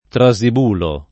vai all'elenco alfabetico delle voci ingrandisci il carattere 100% rimpicciolisci il carattere stampa invia tramite posta elettronica codividi su Facebook Trasibulo [ tra @ ib 2 lo ; alla greca tra @& bulo ] pers. m. stor.